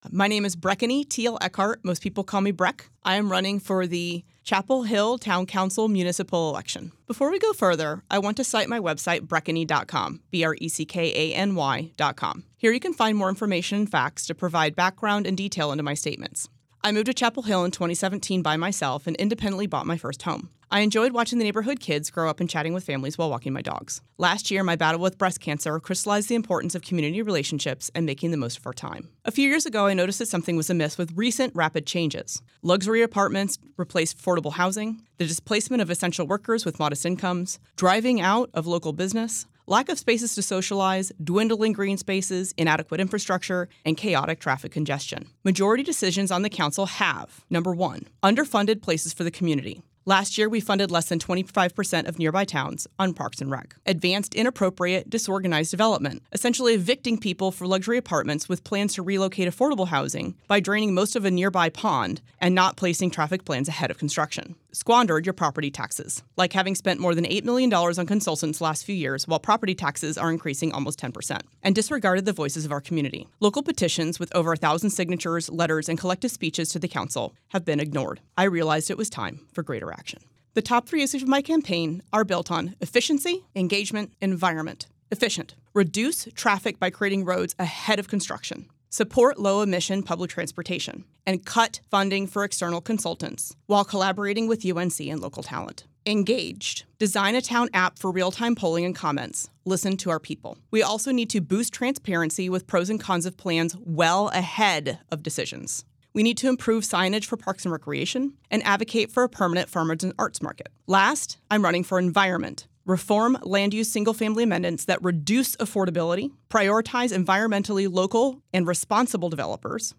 During this local election season, 97.9 The Hill and Chapelboro spoke with candidates for races representing Chapel Hill, Carrboro and Hillsborough.
Their answers (lightly edited for clarity and brevity) are shared here, as well as links to their respective campaign websites or pages.